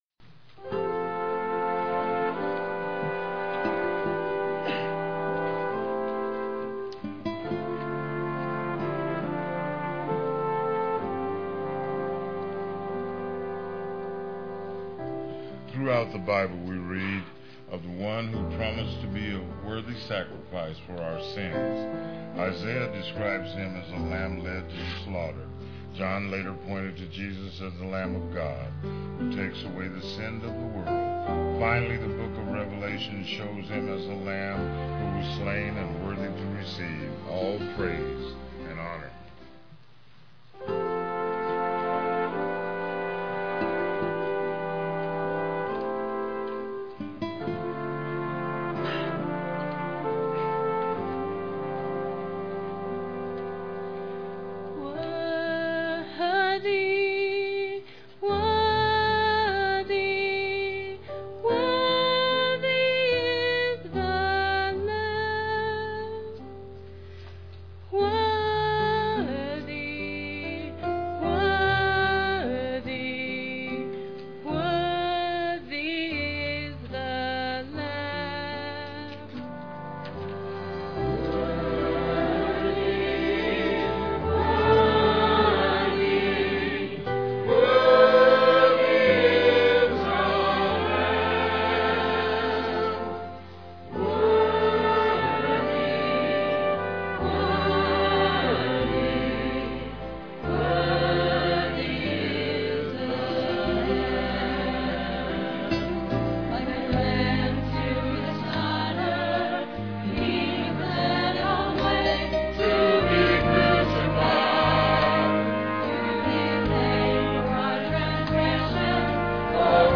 Presented by the ABC Choir and Children/Youth Choir.
GoodFriday2007.mp3